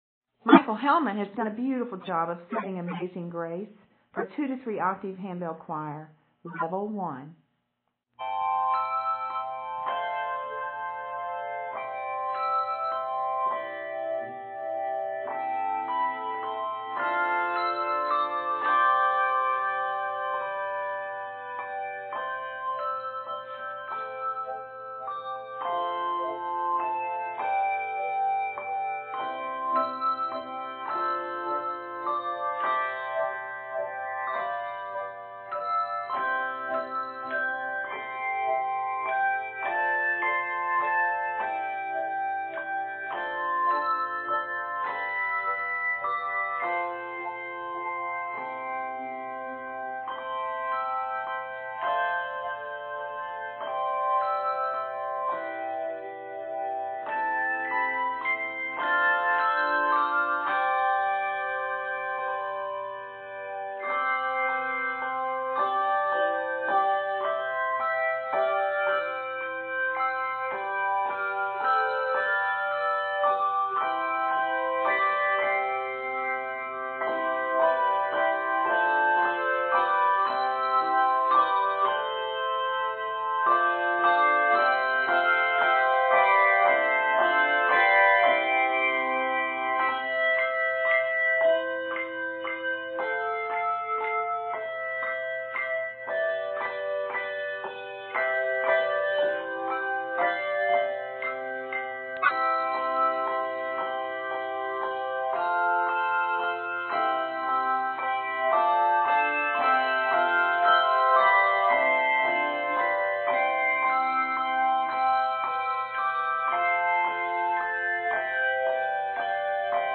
for 2-3 octave beginning handbell choir.
This rendition is arranged in C Major and is 87 measures.